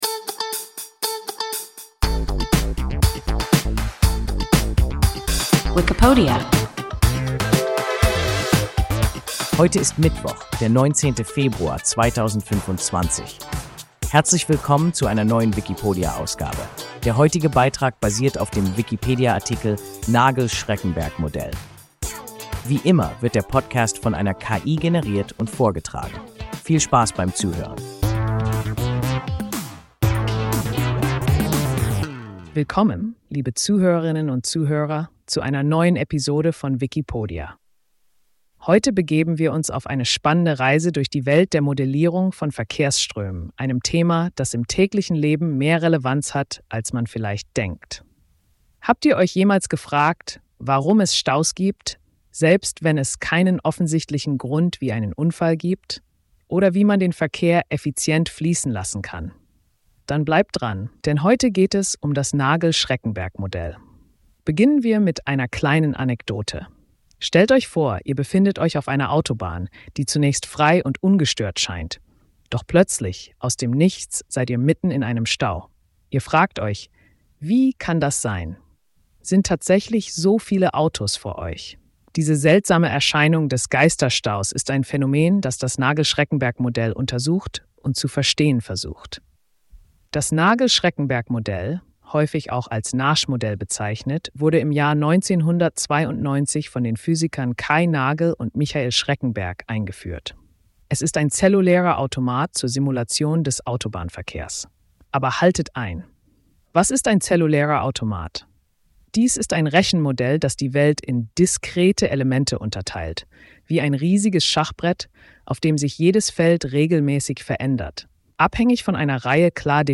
ein KI Podcast